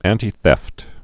(ăntē-thĕft, ăntī-)